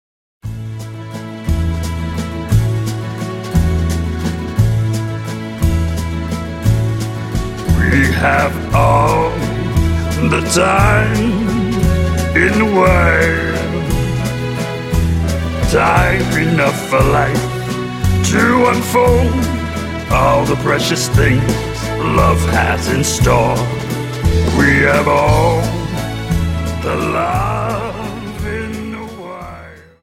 Dance: Viennese Waltz